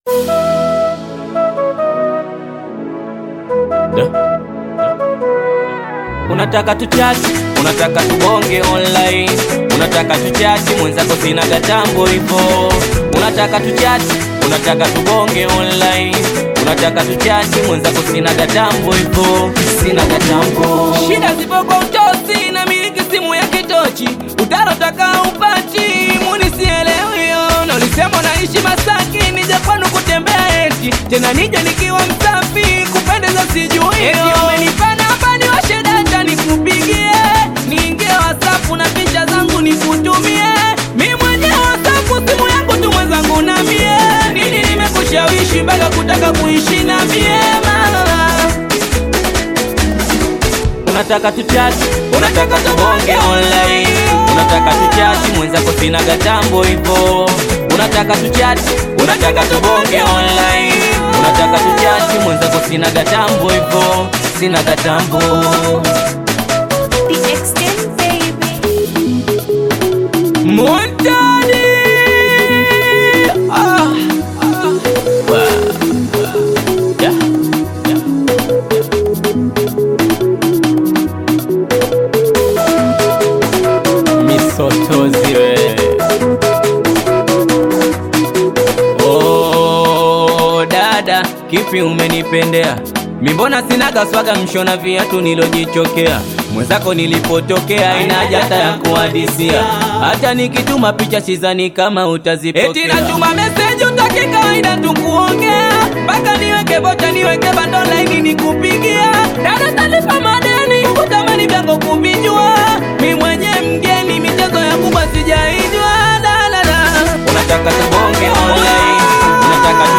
Singeli music track
Tanzanian Bongo Flava artist